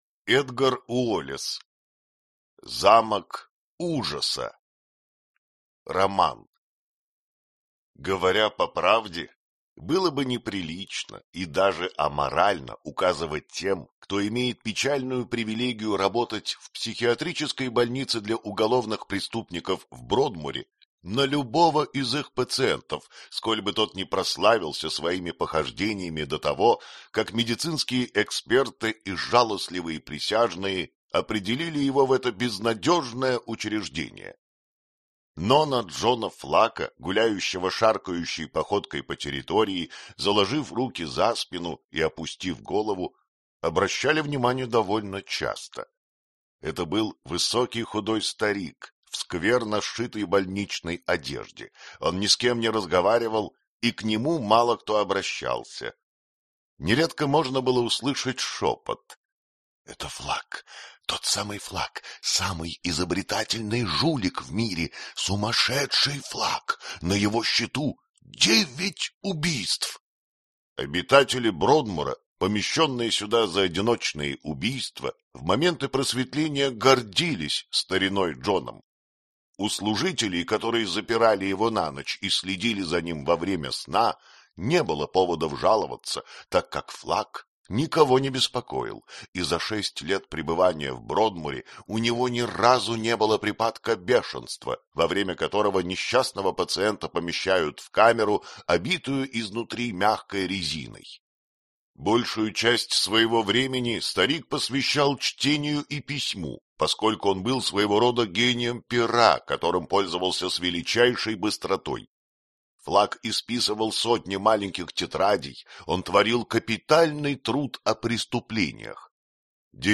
Аудиокнига Замок ужаса | Библиотека аудиокниг